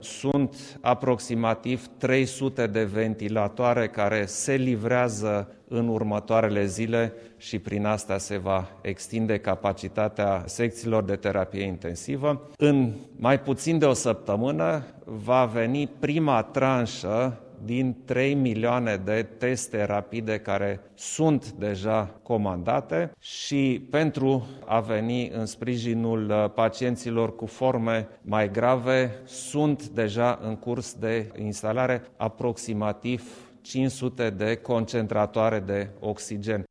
Preşedintele Klaus Iohannis a anunţat că sute de ventilatoare şi o primă tranşă de teste rapide vor fi livrate, în curând, unităţilor medicale din ţară: